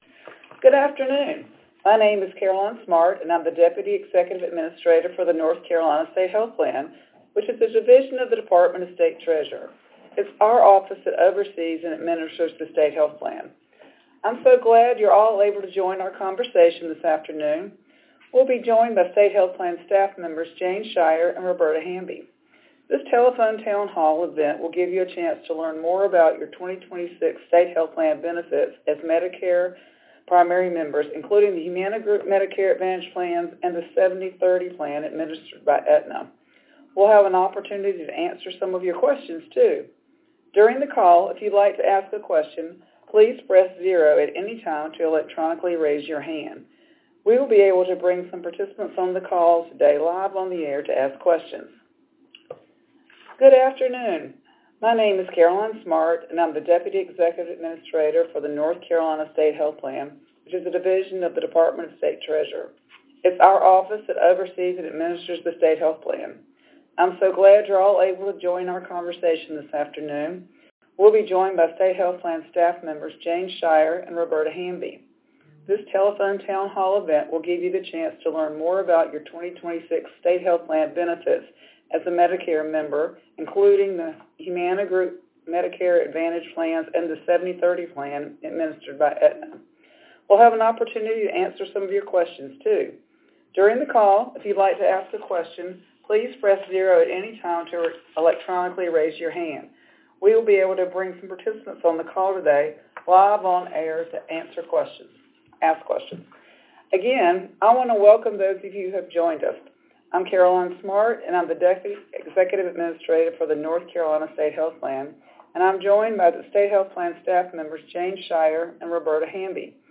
2026 OE Medicare Member Telephone Town Hall Audio Recording